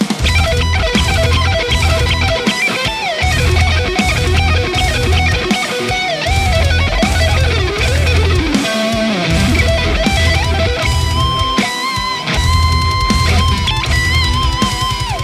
Lead Mix
RAW AUDIO CLIPS ONLY, NO POST-PROCESSING EFFECTS